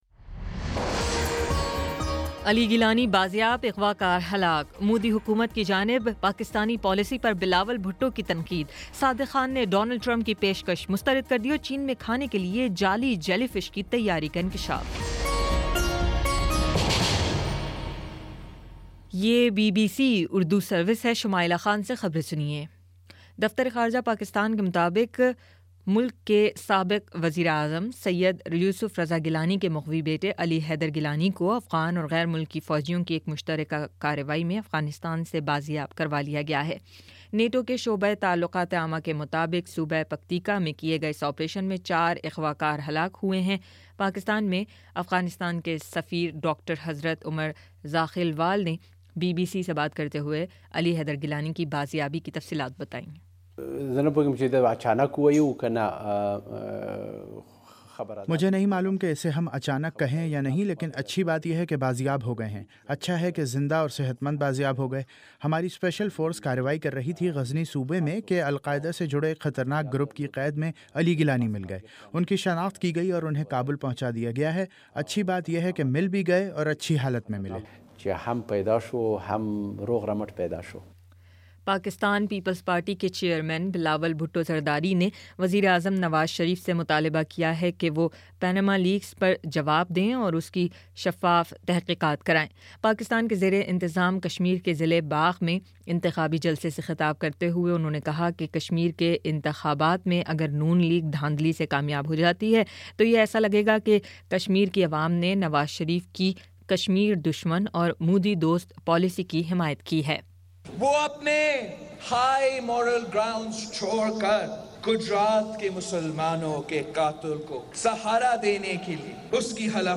مئی 10: شام سات بجے کا نیوز بُلیٹن